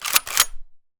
gun_shotgun_cock_01.wav